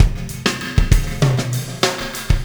Pulsar Beat 03.wav